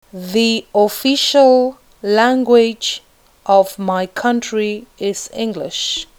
When the word begins with a vowel sound, “the” is pronounce /ði/ the 2.